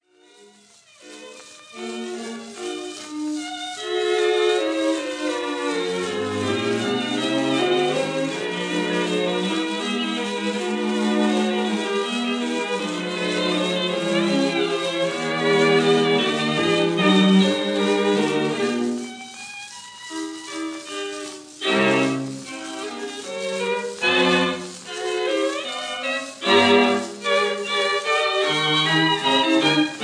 1st violin
2nd violin
viola
cello
plus 2nd viola